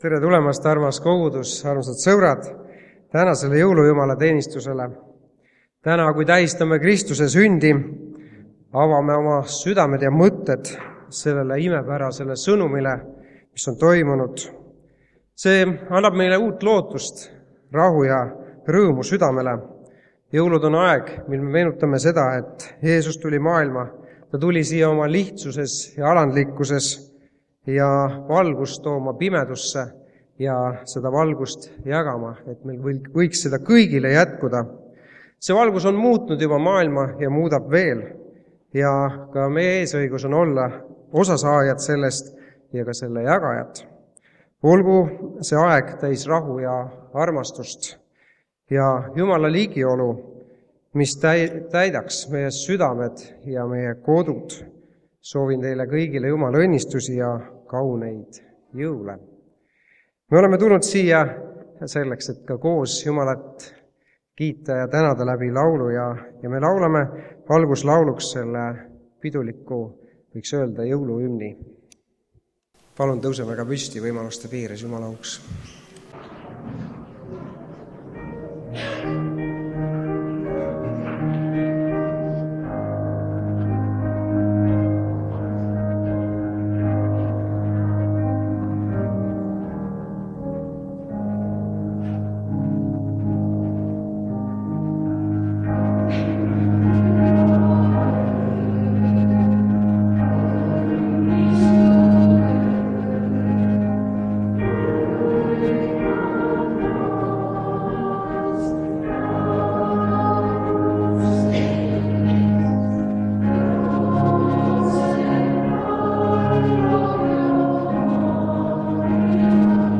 Koosolekute helisalvestused
2024 aasta Jõulude Jumalateenistus kannab pealkirja
Üldlaul
Instrumentaal viiul ja klaver
Laul kitarriga